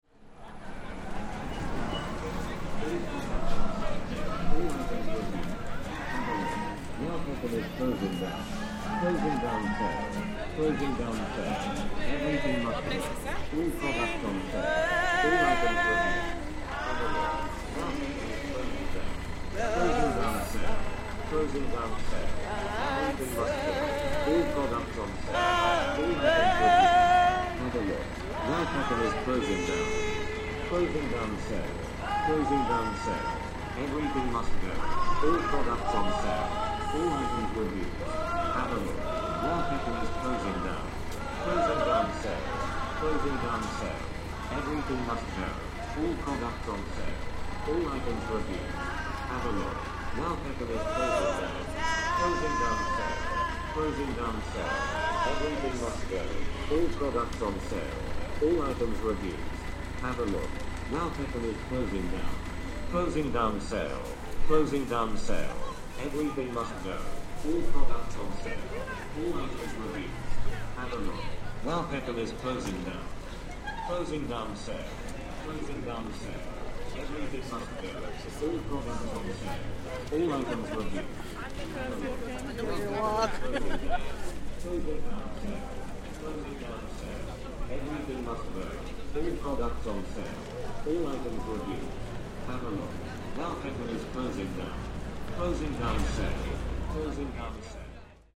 Singers from a local Cameroonian church in Peckham take to the streets to sing a version of “Amazing Grace”, accompanied by an acoustic guitar.
The soundscape is lent a surreal edge by the robotic recorded voice advertising a closing down sale for Wow Peckham, the shop directly behind us.